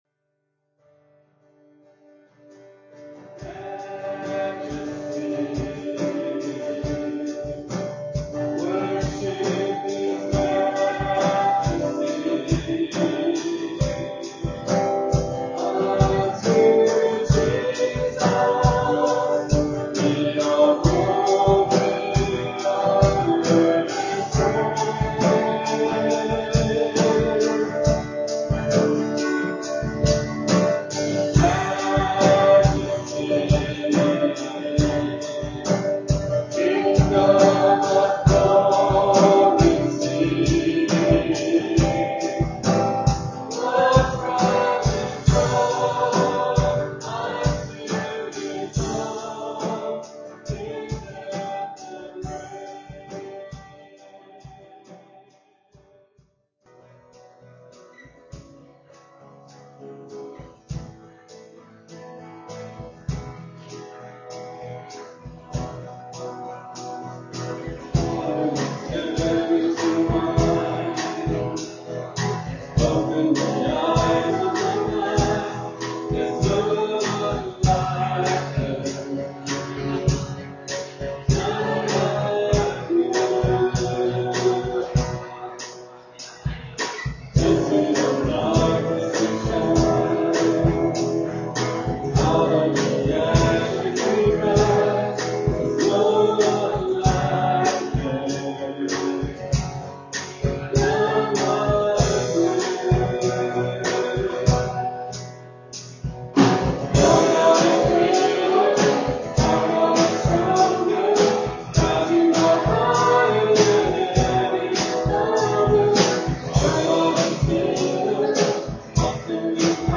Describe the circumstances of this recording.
at Ewa Beach Baptist Church.